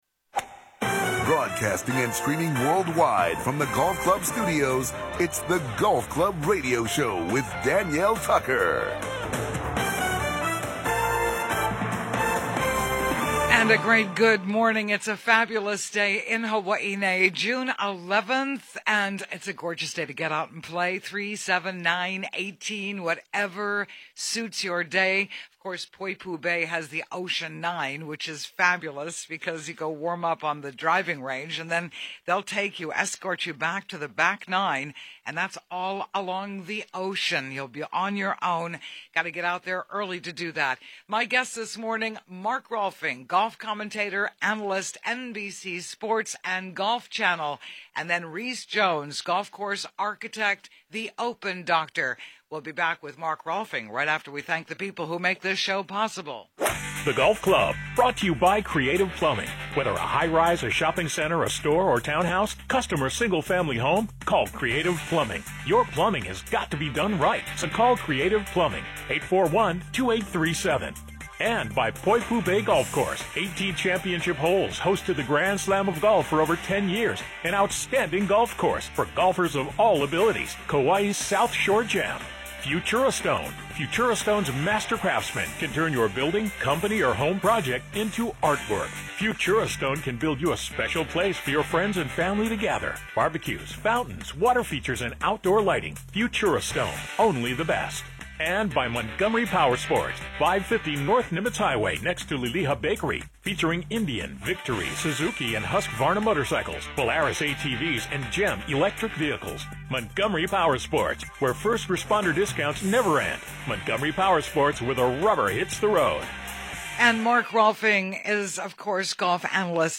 Golf Club Radio Show 6/11/2016
Mark Rolfing: Golf Analyst and On Course Commentator, NBC and Golf Channel